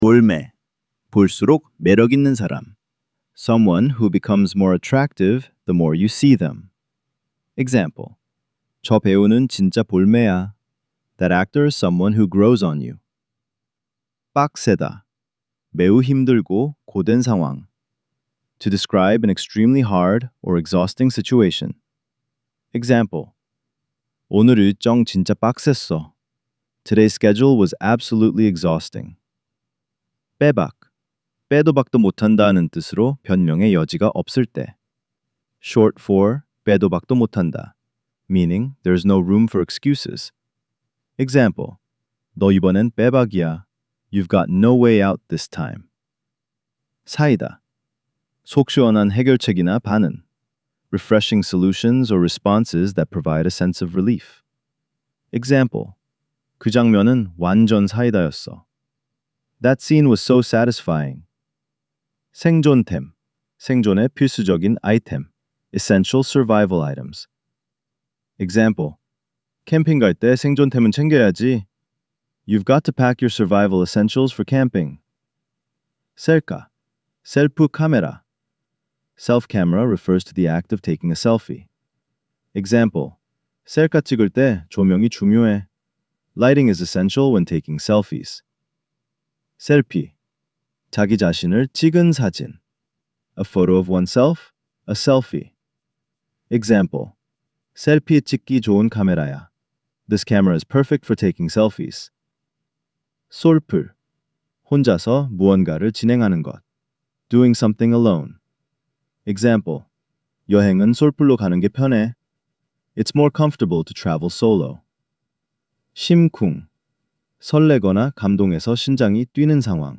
5) Korean Slang Decoded: Vol. 05 – Audio Narration